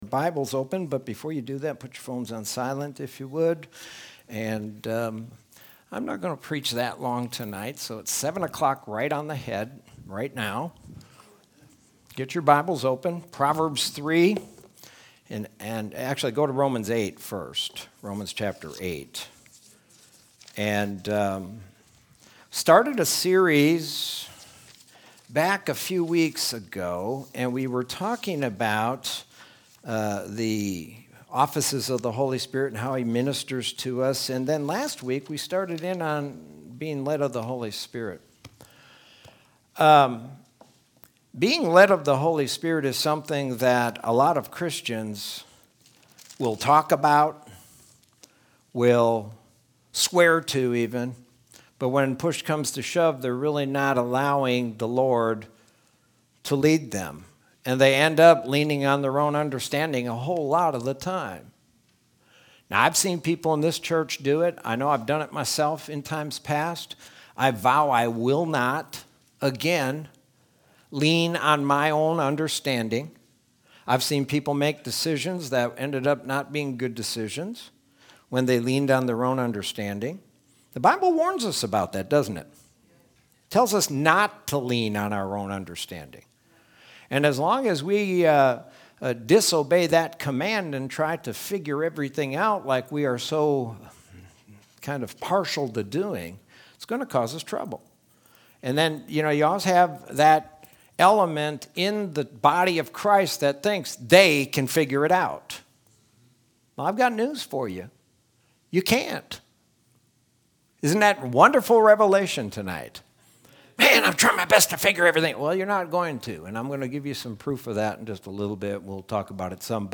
Sermon from Wednesday, September 23rd, 2020.